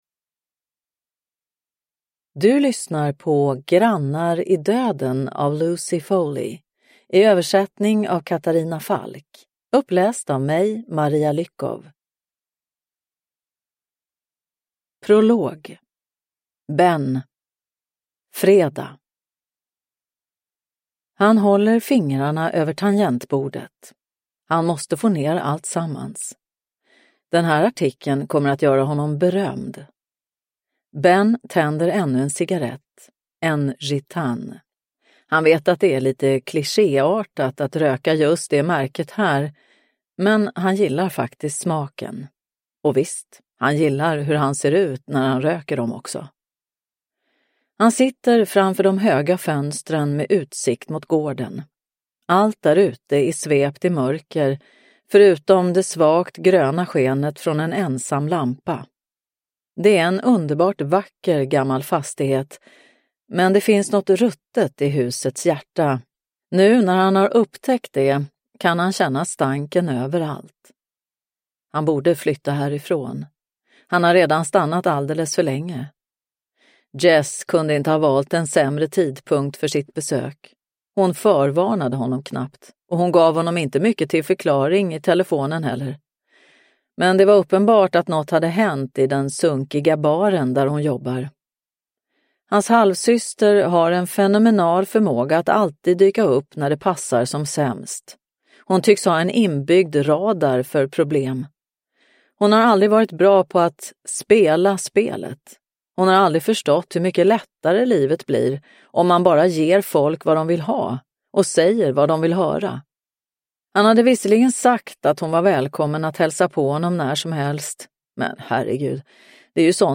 Grannar i döden – Ljudbok – Laddas ner